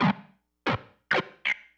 synthFX01.wav